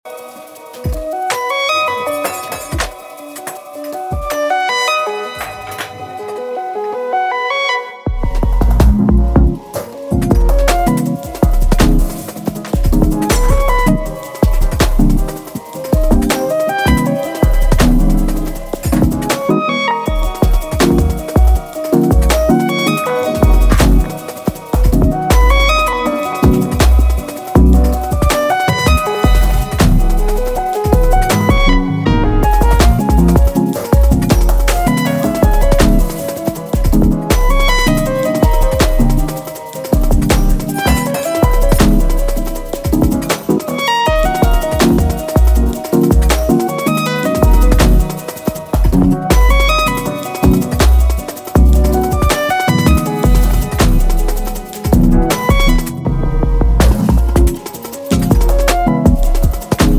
Electronix Jungle Breaks Electronica